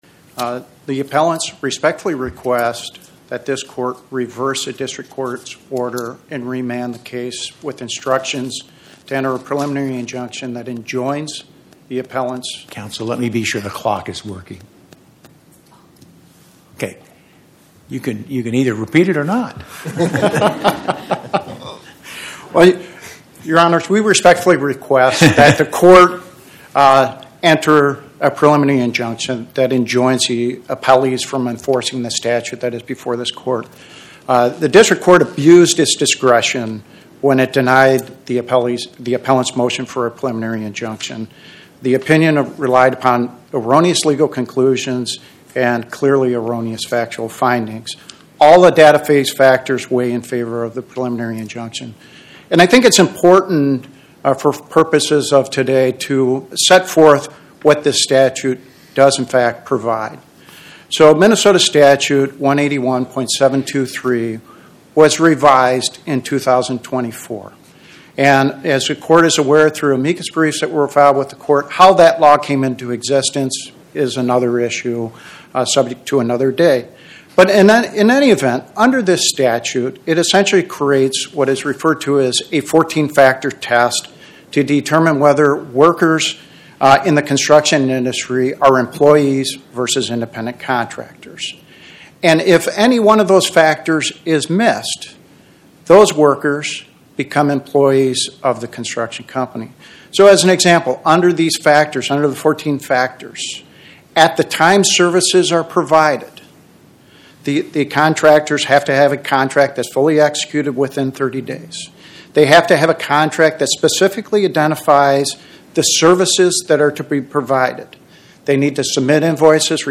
Builders vs Nicole Blissenbach Podcast: Oral Arguments from the Eighth Circuit U.S. Court of Appeals Published On: Tue Sep 16 2025 Description: Oral argument argued before the Eighth Circuit U.S. Court of Appeals on or about 09/16/2025